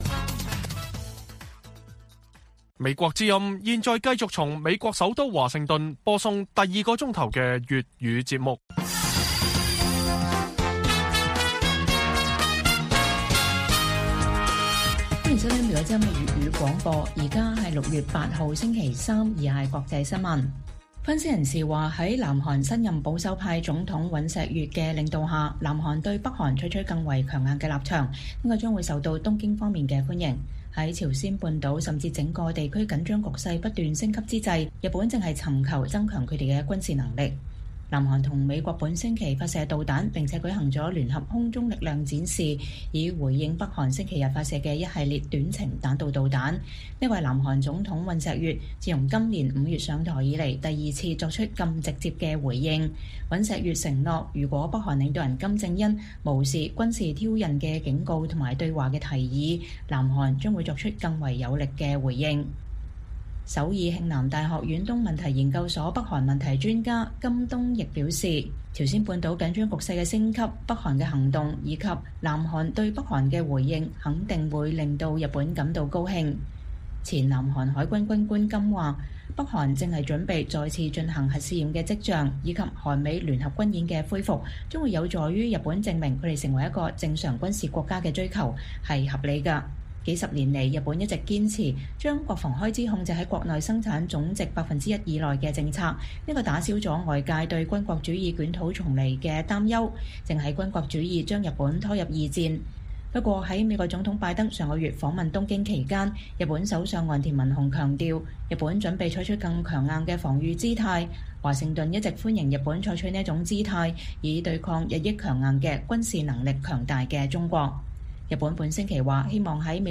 粵語新聞 晚上10-11點: 南韓對北韓的強硬回應被認為有助於推動日本軍力發展